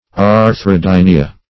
Search Result for " arthrodynia" : The Collaborative International Dictionary of English v.0.48: Arthrodynia \Ar`thro*dyn"i*a\, n. [NL., fr. Gr.
arthrodynia.mp3